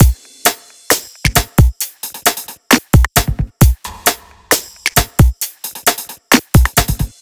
HP133BEAT1-L.wav